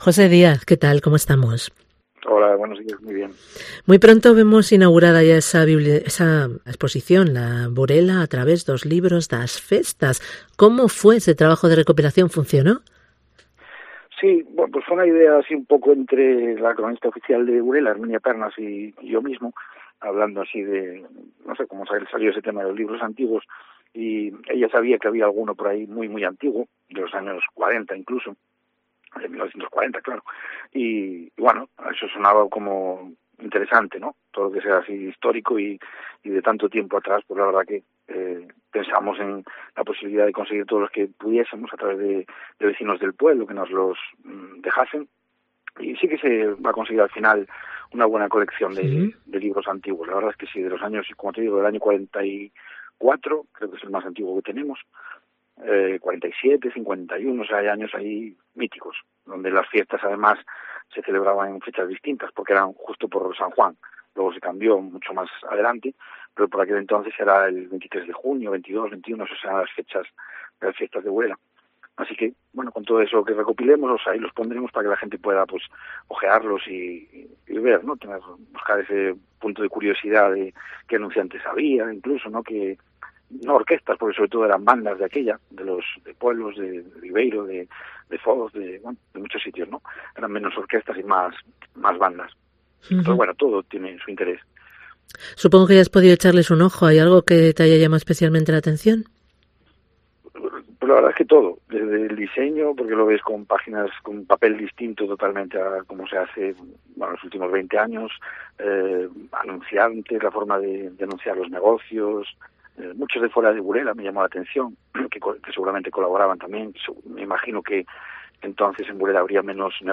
El concejal de Cultura de Burela habla de la muestra de antiguos Libros de Fiestas que inaugura este jueves